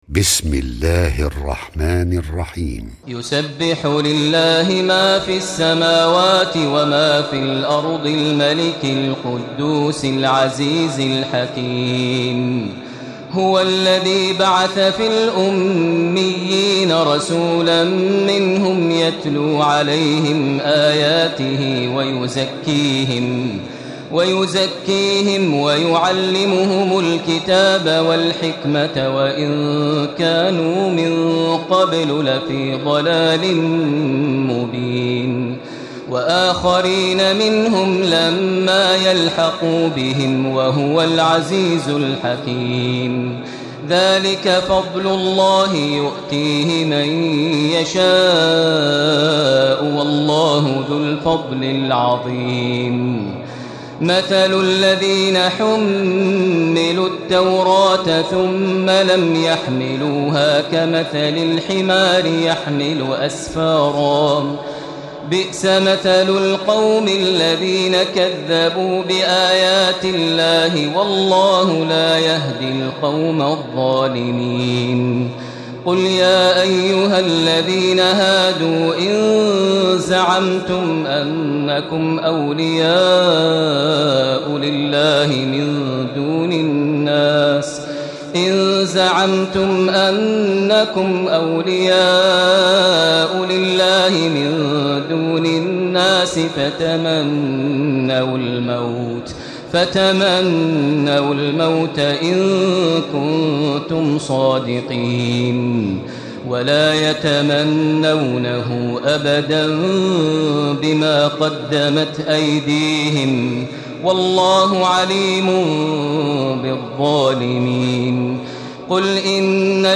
تراويح ليلة 27 رمضان 1436هـ من سورة الجمعة الى التحريم Taraweeh 27 st night Ramadan 1436H from Surah Al-Jumu'a to At-Tahrim > تراويح الحرم المكي عام 1436 🕋 > التراويح - تلاوات الحرمين